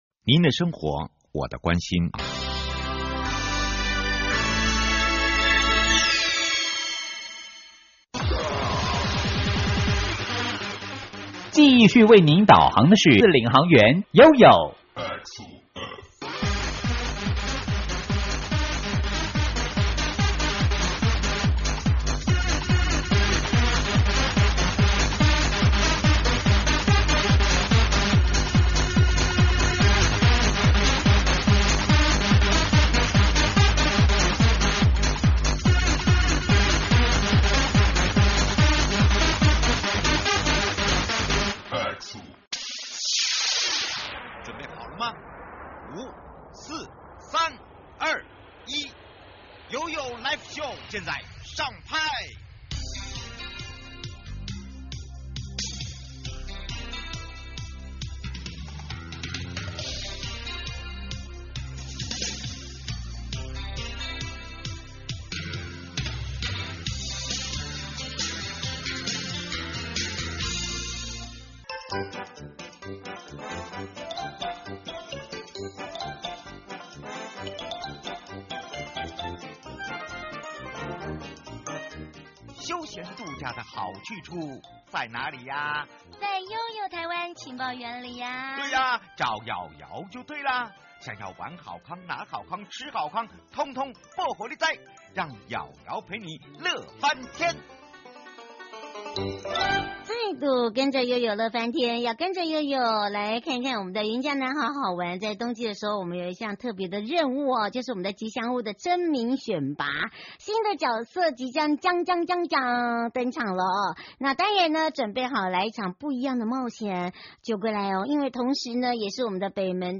花蓮太平洋燈會2/7登場「奇蹟」即將登陸日出大道 受訪者